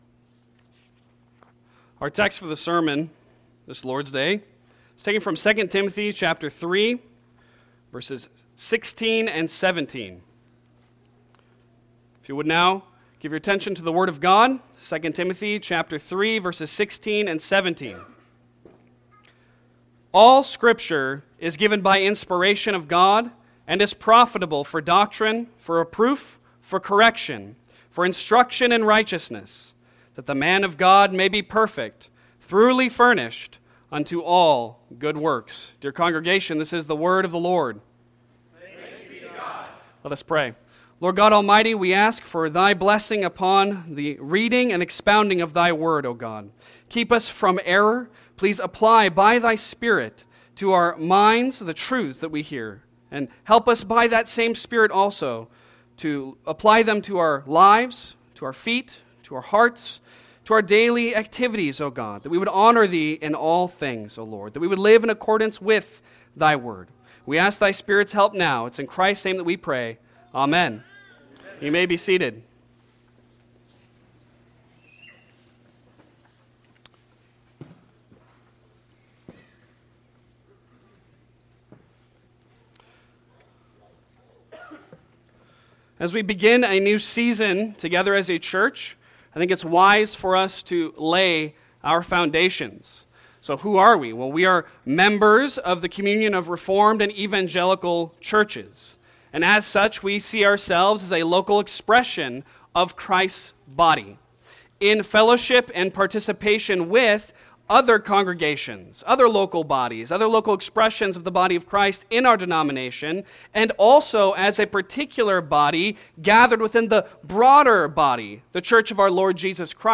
Passage: 2 Timothy 3:16-17 Service Type: Sunday Sermon